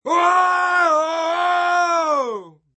Descarga de Sonidos mp3 Gratis: grito 17.